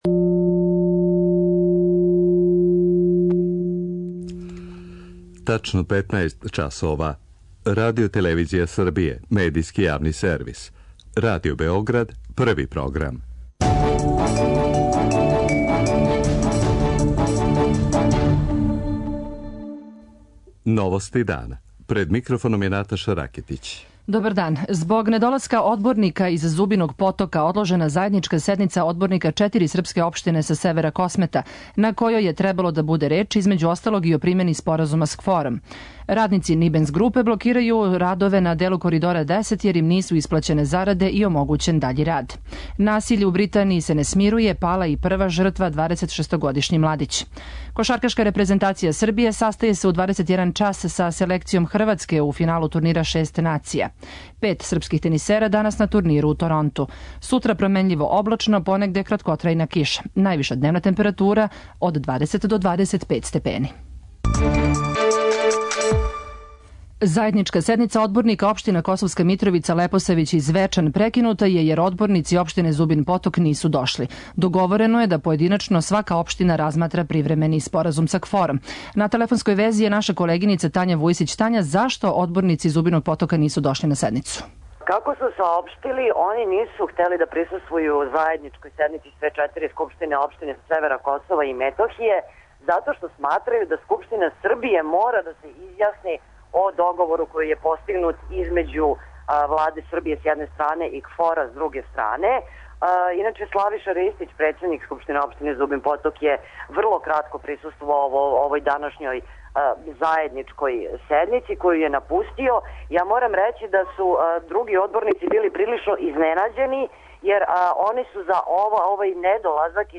За Новости дана говори Борислав Стефановић, шеф преговарачког тима у разговорима са Приштином.
преузми : 14.84 MB Новости дана Autor: Радио Београд 1 “Новости дана”, централна информативна емисија Првог програма Радио Београда емитује се од јесени 1958. године.